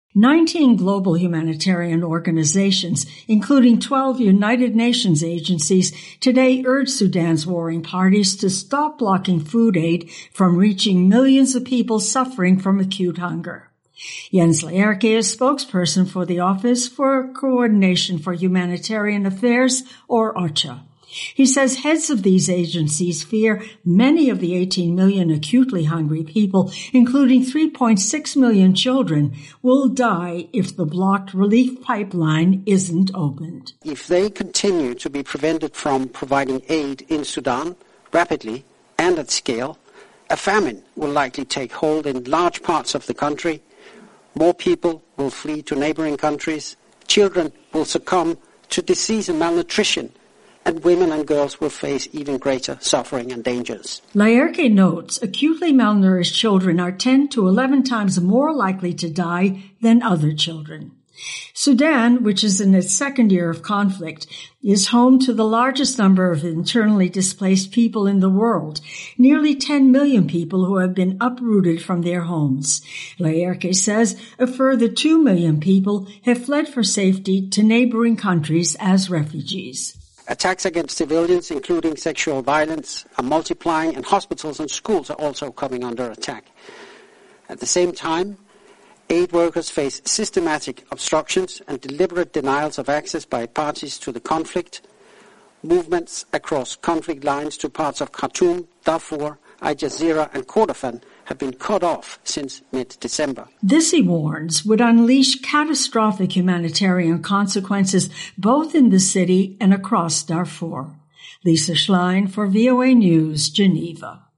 reports for VOA from Geneva.